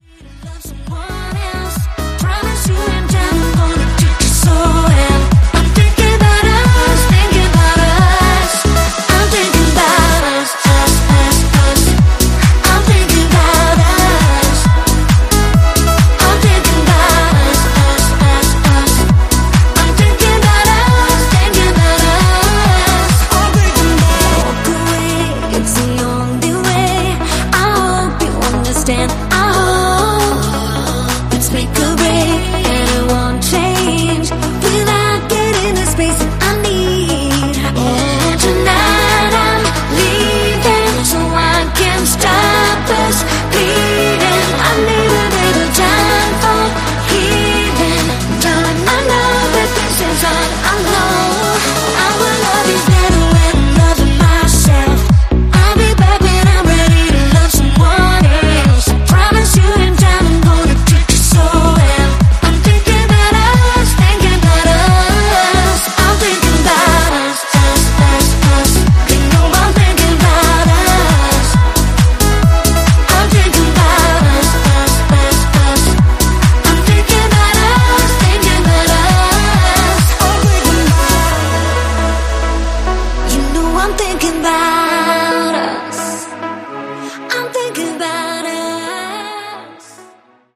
ジャンル(スタイル) POP / HOUSE